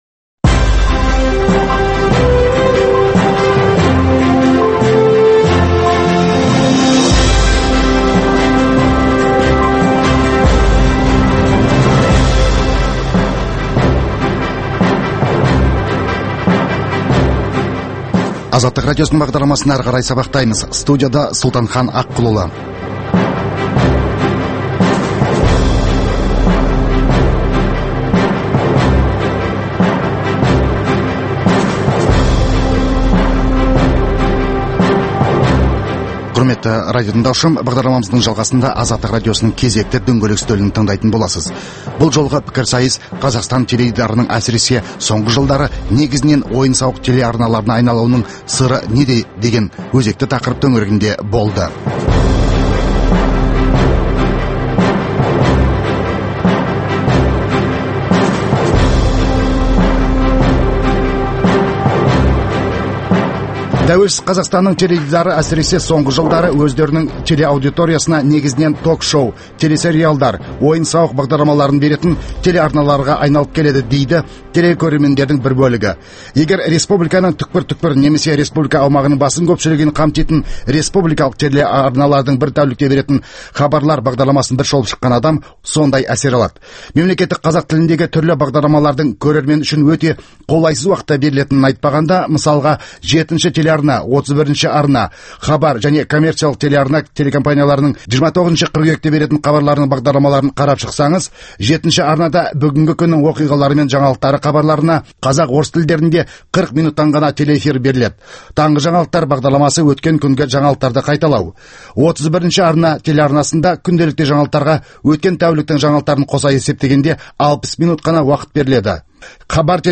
Азаттық радиосының дөңгелек үстел талқылауы осы тақырыпқа арналды.